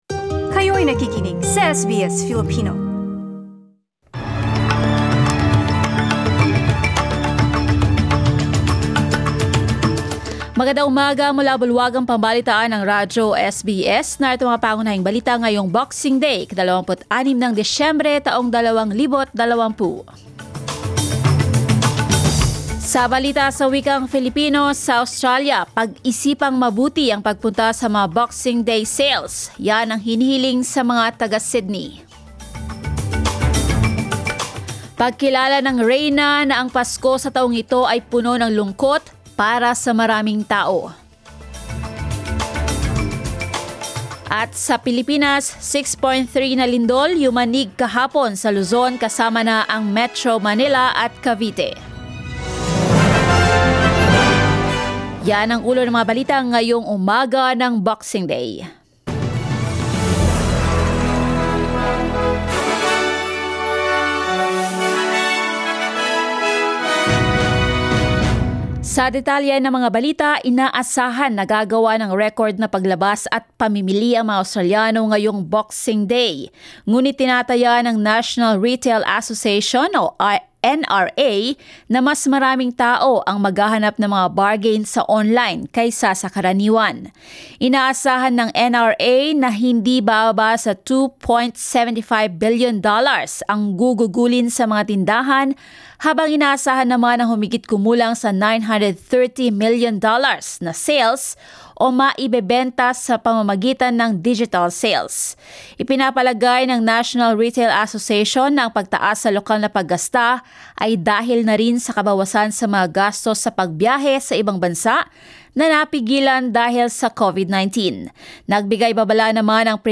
SBS News in Filipino, Saturday 26 December